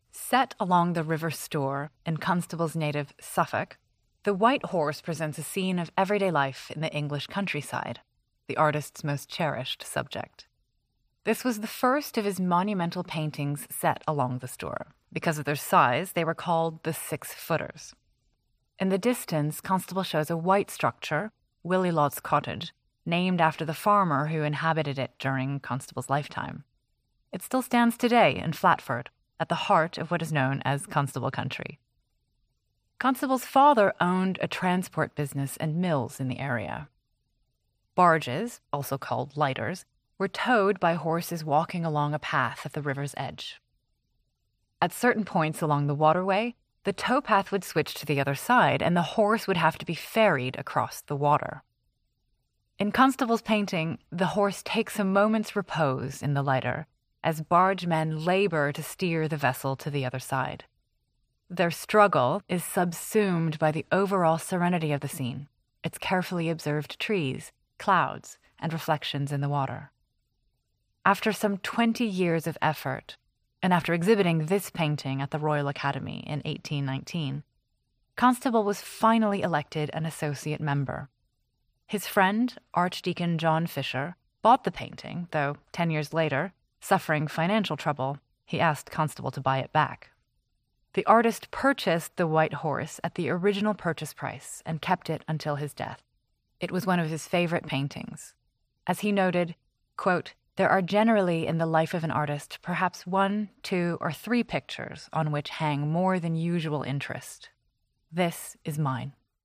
Audioguide without script